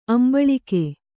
♪ ambaḷike